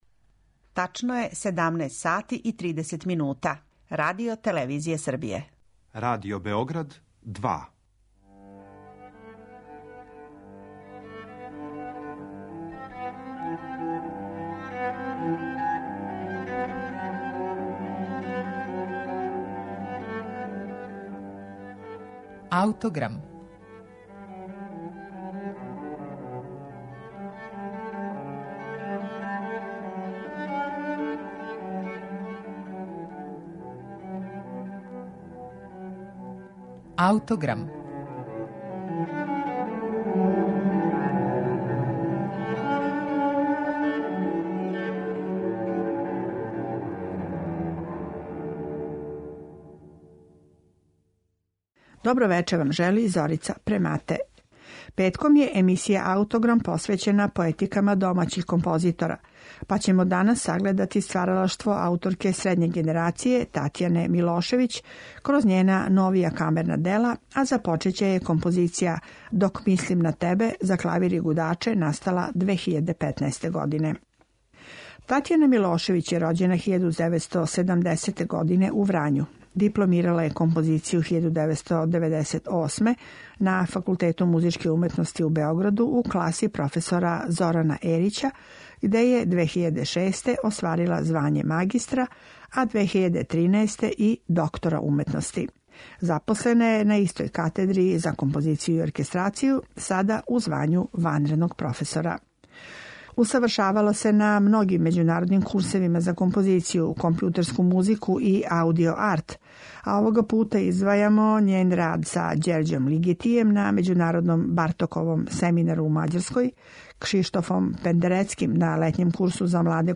за клавир и гудаче